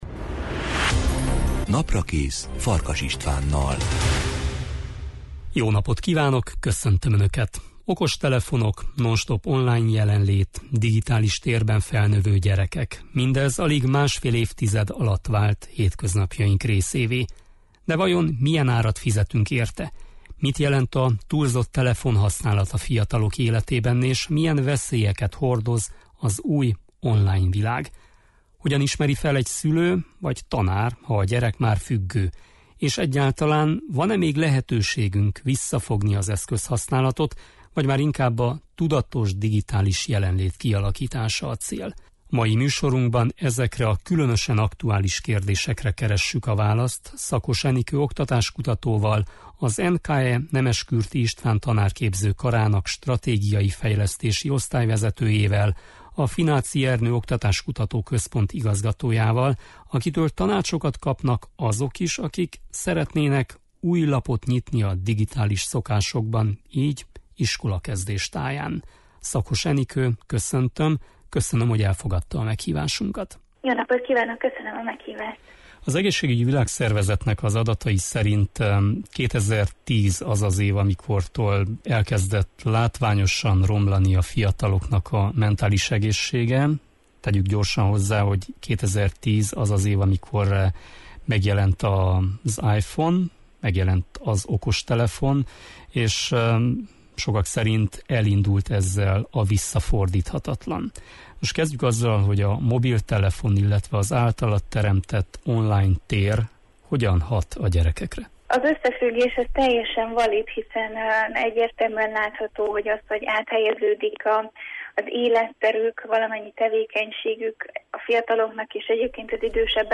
Mai műsorunkban ezekre a kérdésekre keressük a választ vendégünkkel